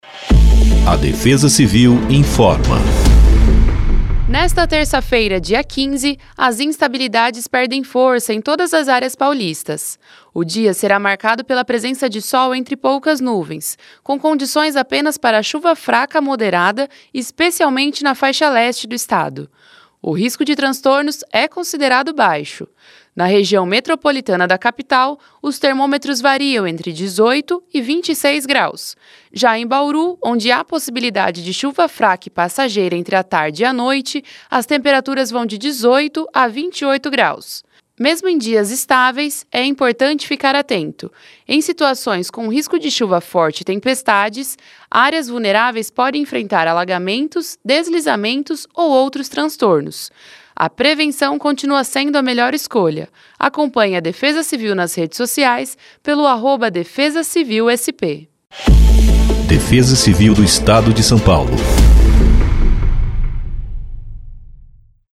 Defesa-Civil-Boletim-Previsao-do-Tempo-para-1504-Spot-1.mp3